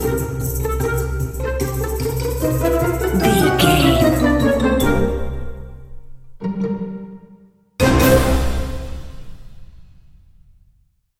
Uplifting
Lydian
flute
oboe
strings
orchestra
cello
double bass
percussion
silly
circus
goofy
cheerful
perky
Light hearted
quirky